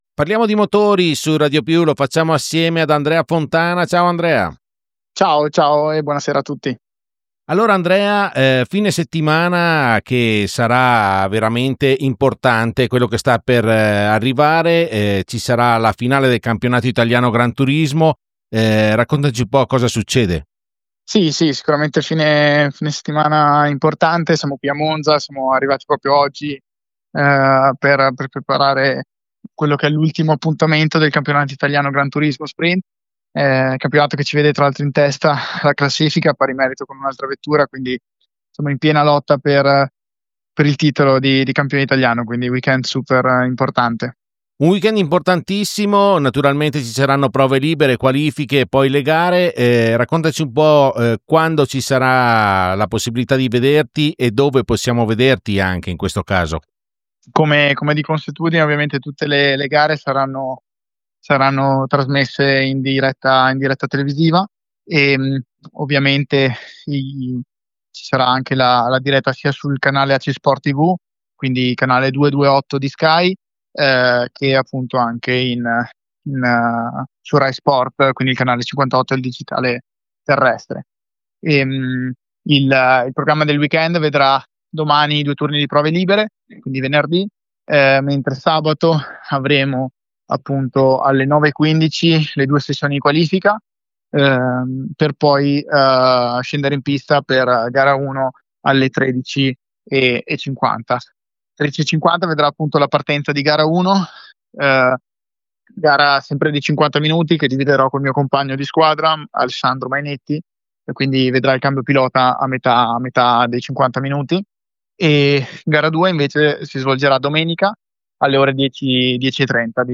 Le gare decisive si terranno sabato 25 ottobre alle 13:50 e domenica 26 ottobre alle 10:30, promettendo emozioni fino all’ultimo decimo. L’INTERVISTA